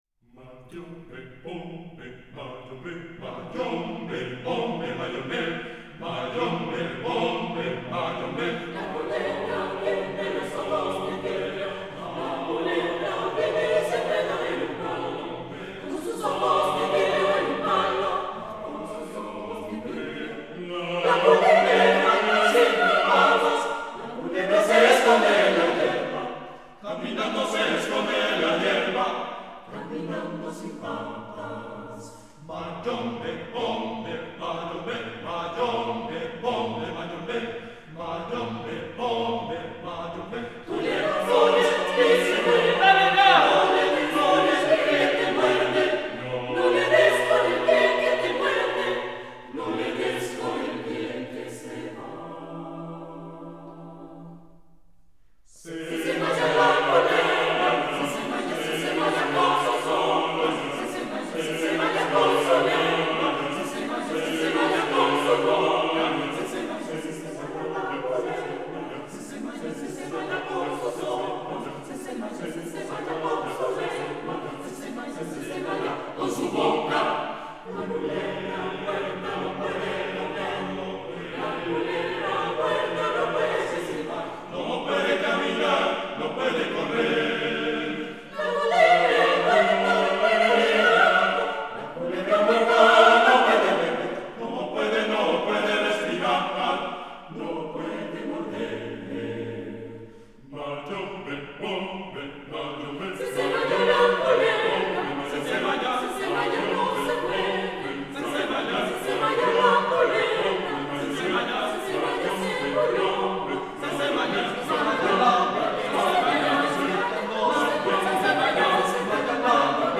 Música vocal